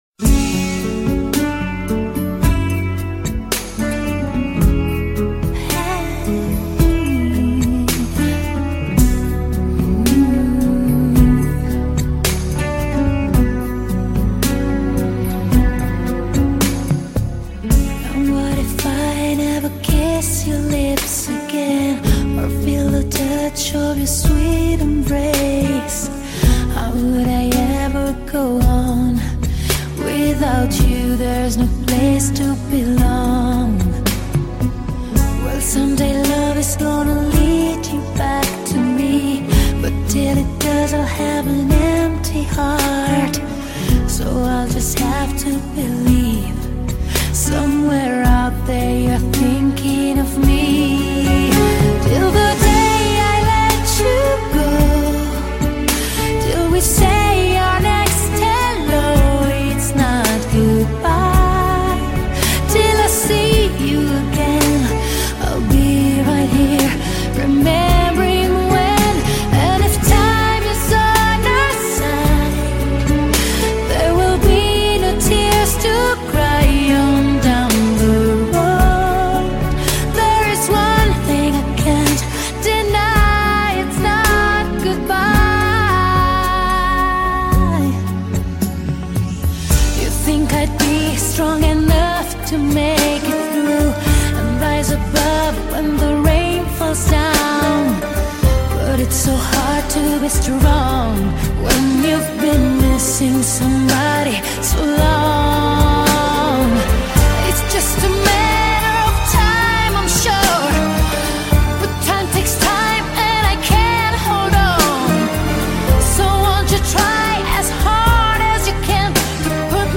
медленные песни , Медляки